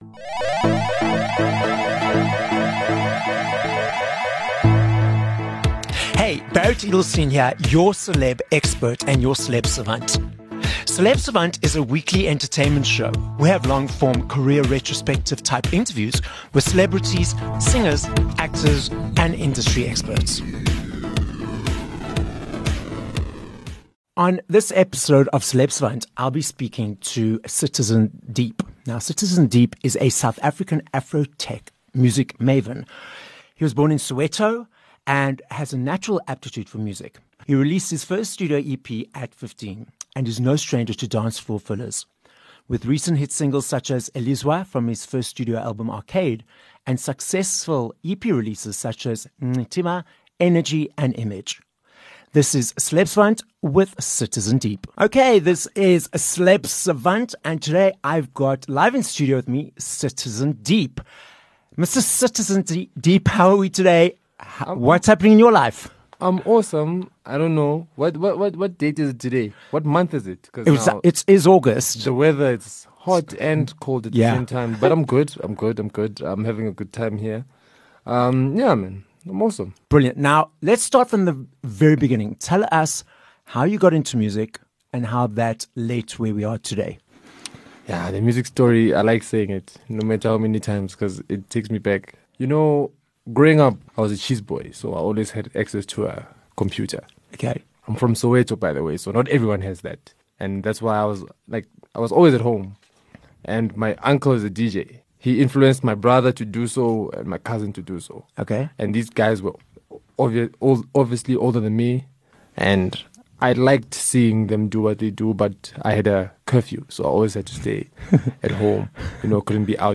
26 Sep Interview